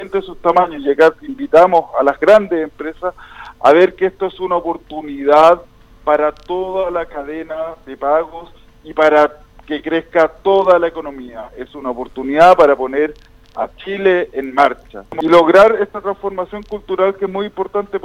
Así lo dio a conocer en el programa Haciendo Ciudad de Radio Sago, el Subsecretario de Economía, Ignacio Guerrero, quien se refirió a la importancia de oxigenar el sistema en materia de financiamiento.